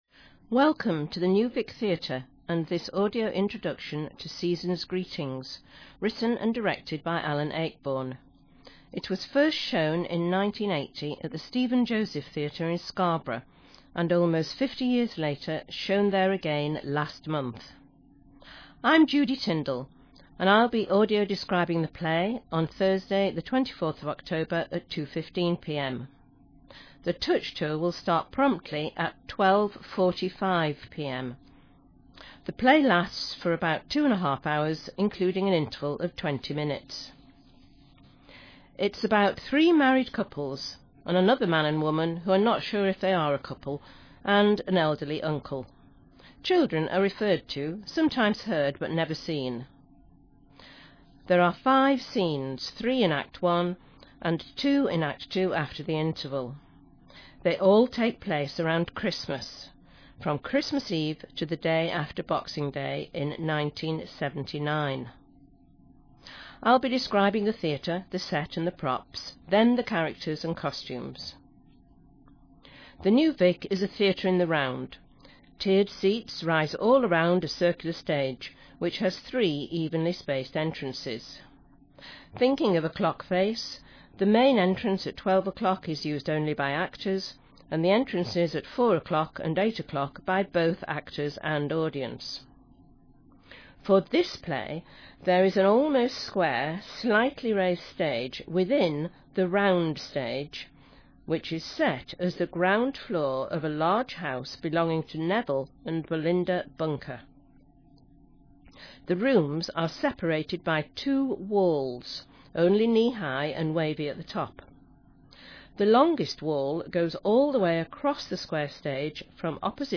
Please click here to listen to the Seasons Greetings Audio Description introduction.